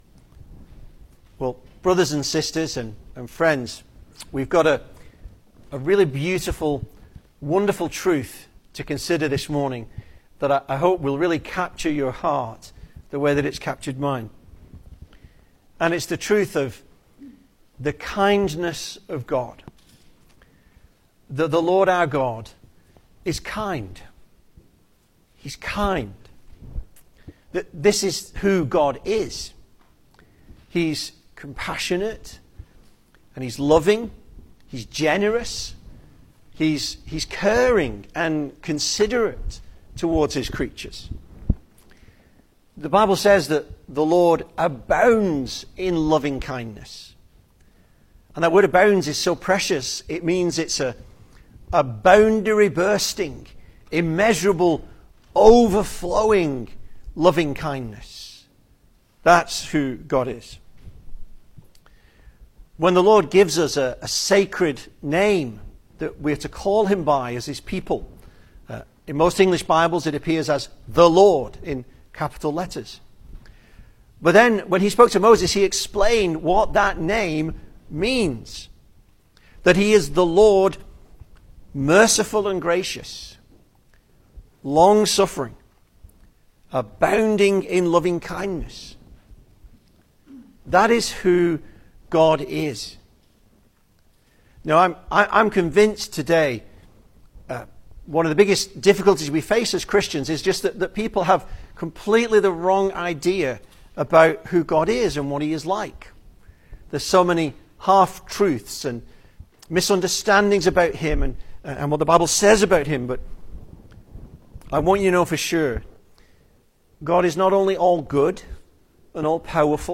Service Type: Sunday Morning
Single Sermons